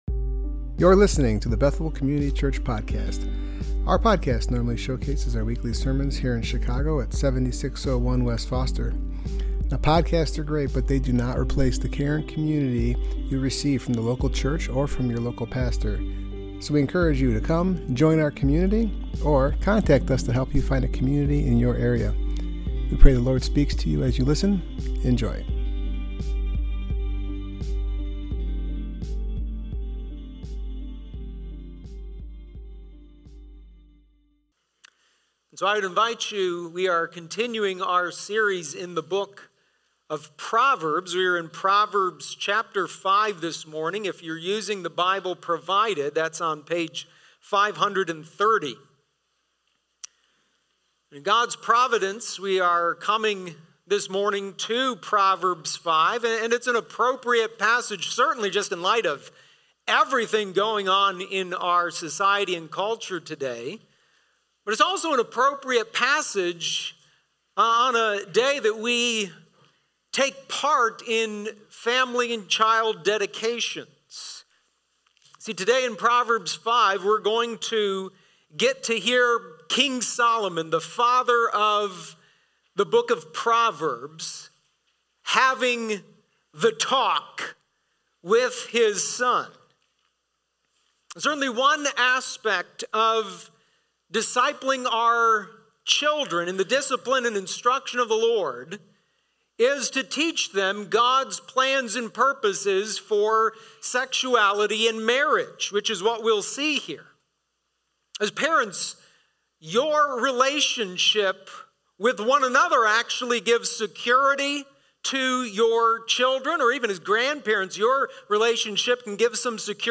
Passage: Proverbs 5:1-23 Service Type: Worship Gathering